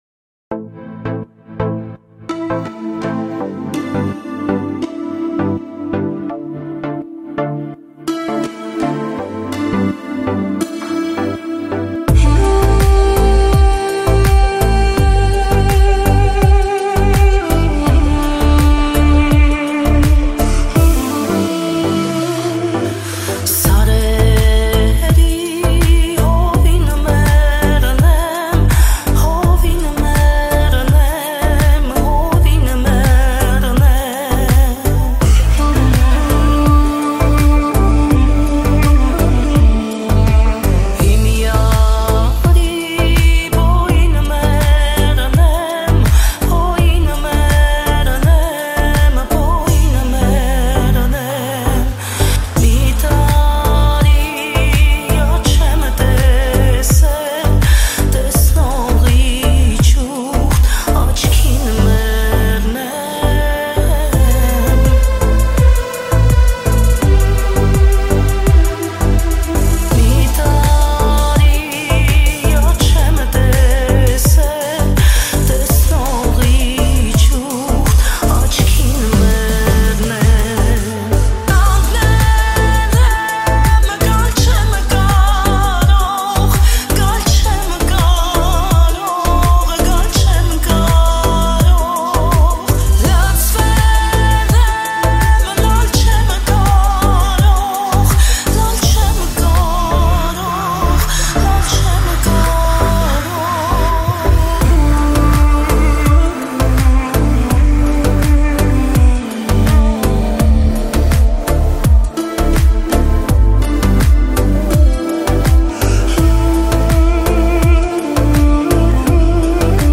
Танцевальный